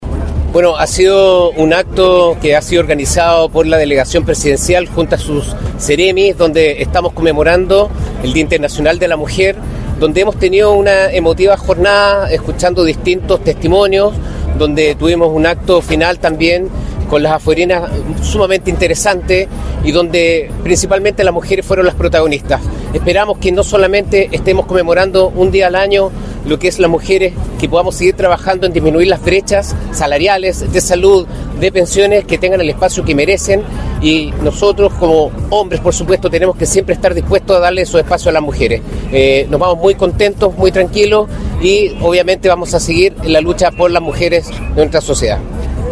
Ante más de 200 personas, entre ellas dirigentas, vecinas, artistas, académicas y autoridades, se conmemoró en el Faro Monumental de La Serena el acto oficial por el Día Internacional de la Mujer.
ACTO-8M-Gobernador-Regional-Cristobal-Julia.mp3